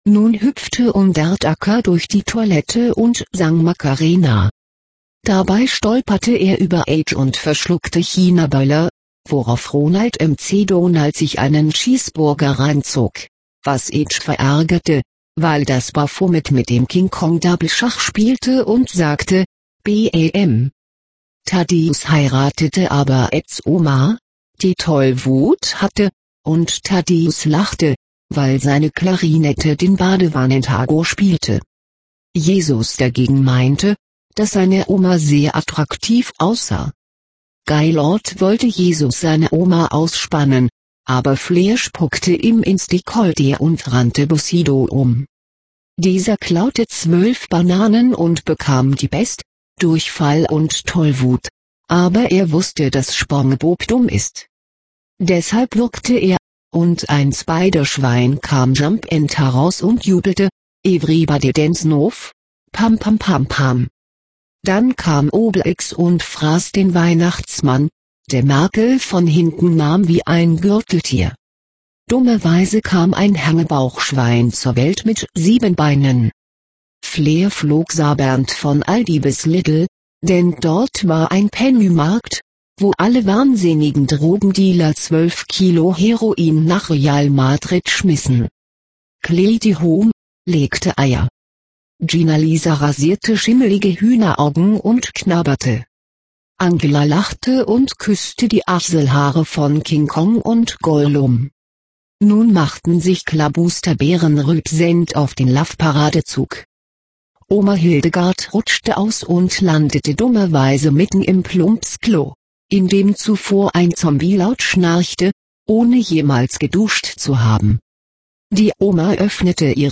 Das Hörbuch zur endlosen Story, Seite 17